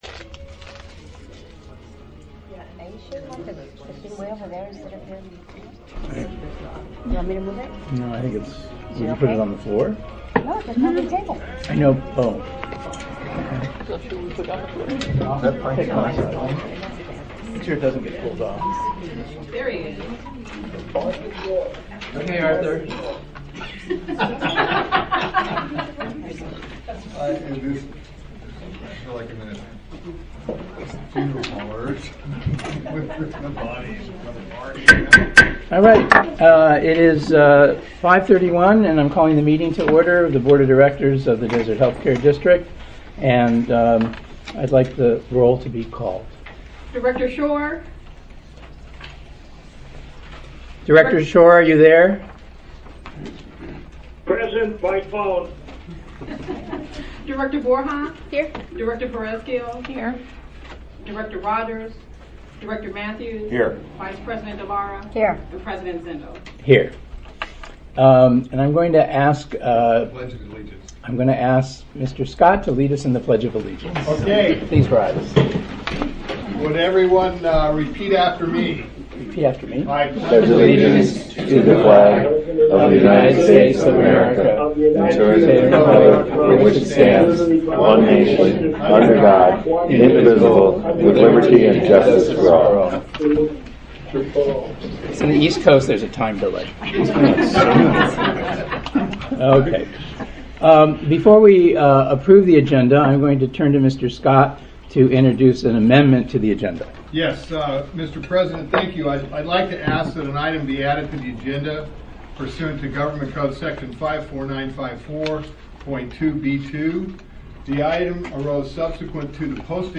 Board of Directors Meeting - District - Family YMCA of the Desert